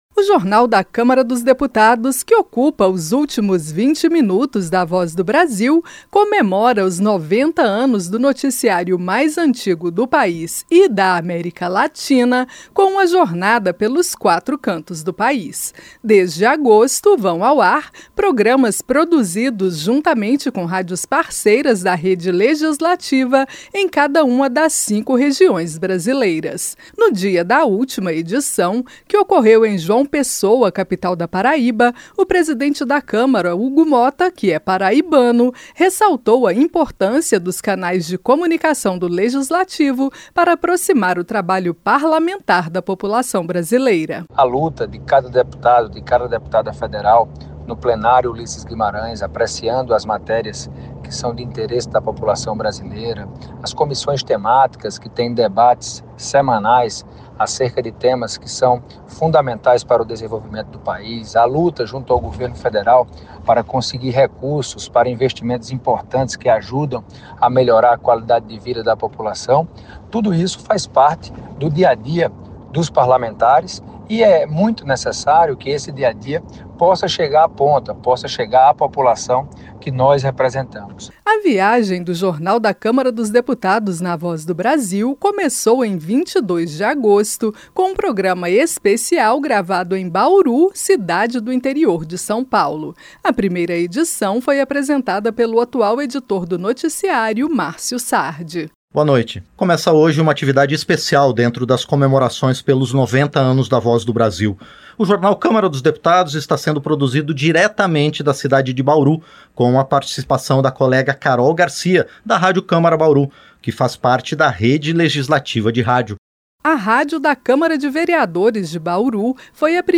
JORNAL DA CÂMARA NA VOZ DO BRASIL CONCLUI A JORNADA PELO BRASIL. A REPÓRTER